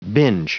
Prononciation du mot binge en anglais (fichier audio)
Prononciation du mot : binge